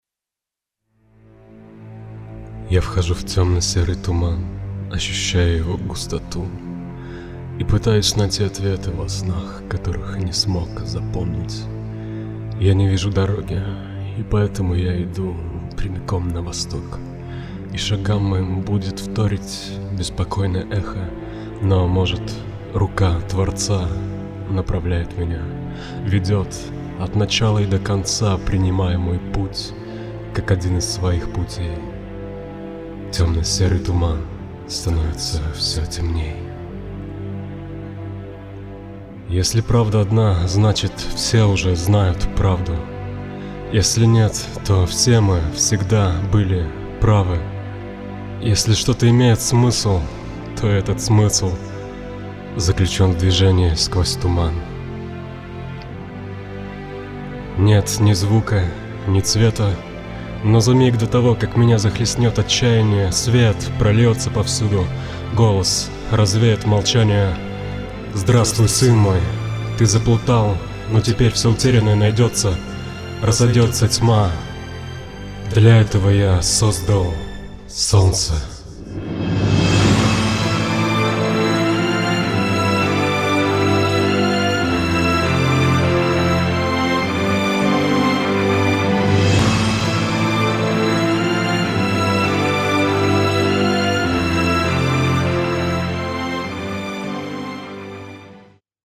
Я уже и забыла, как твой красивый голос ложится на стихи...
Читай нам больше стихов)